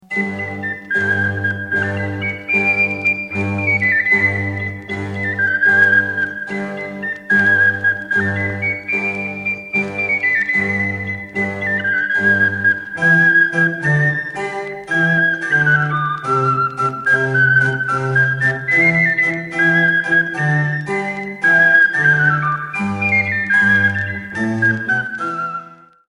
Canson balladée | originale